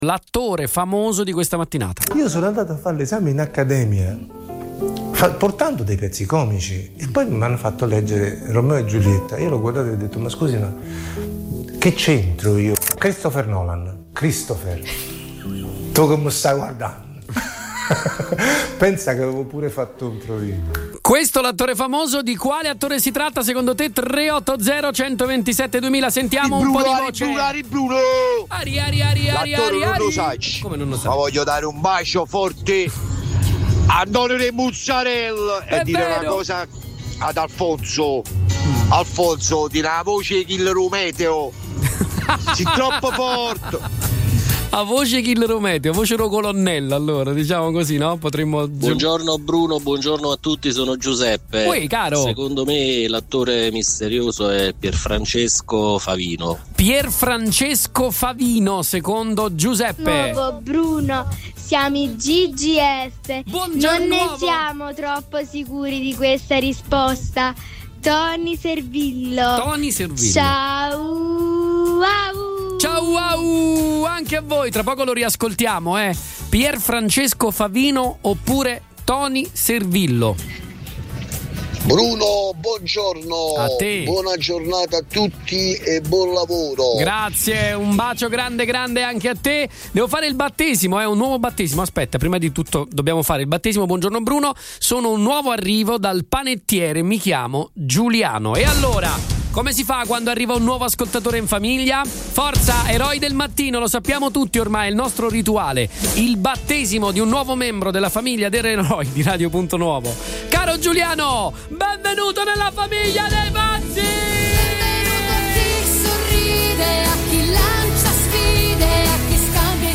Stamattina abbiamo giocato con la voce di un attore italiano : Piefrancesco Favino